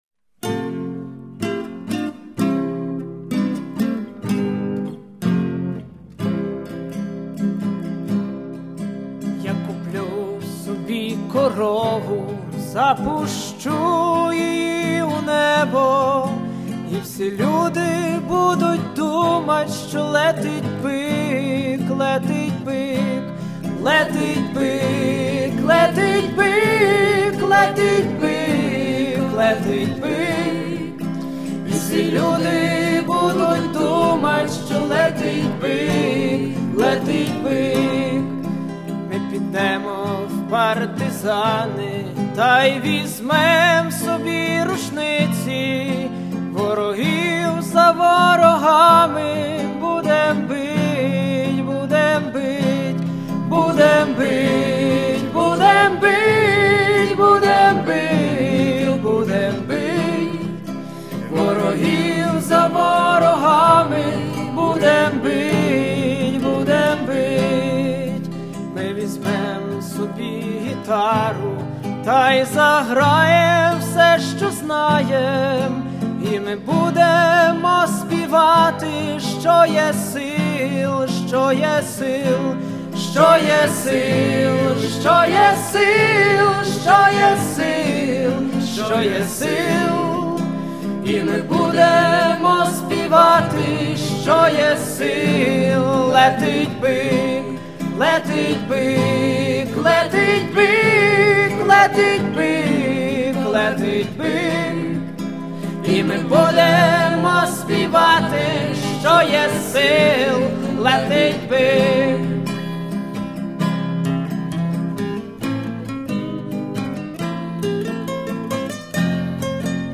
вокал
фон-вокал
соло-гітара